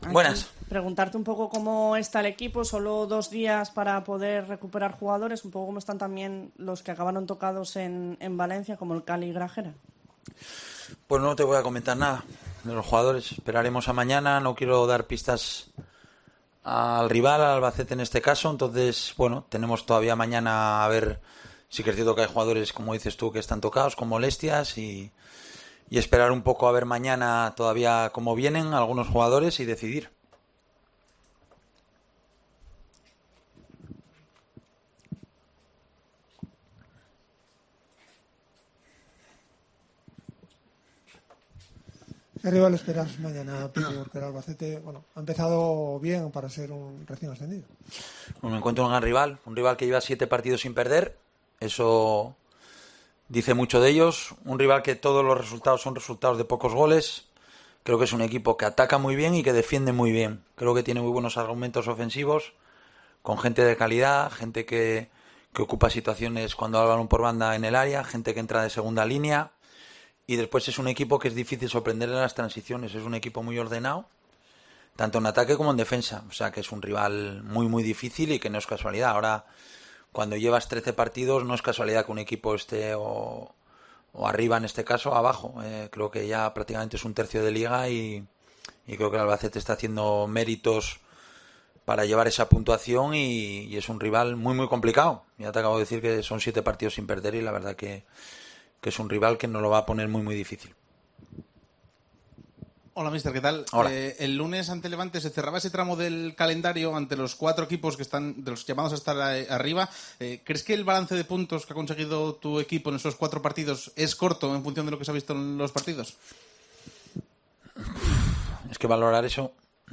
Rueda de prensa Abelardo (previa Albacete)